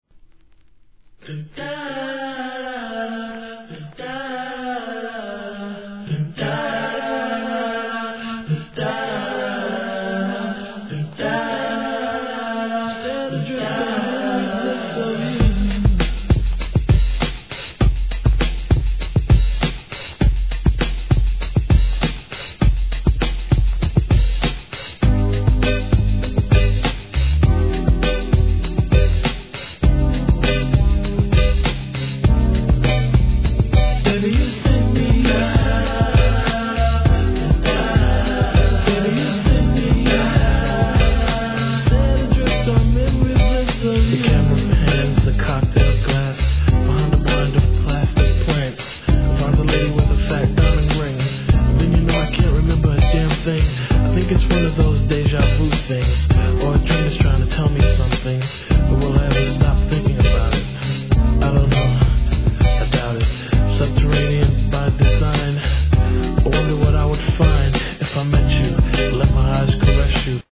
HIP HOP/R&B
超爽やか